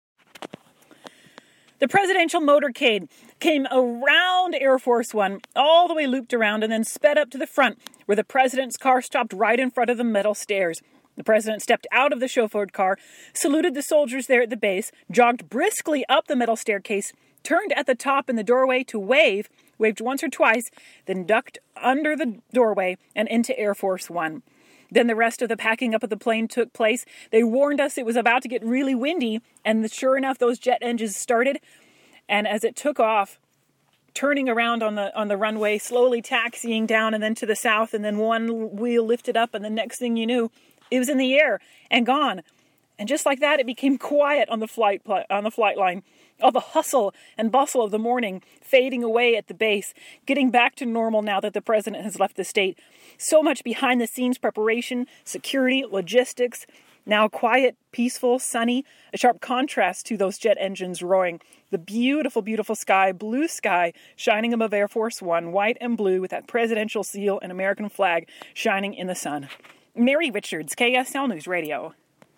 Air Force One departs Hill Air Force Base
A sunny day, a calm flight line, a waving President.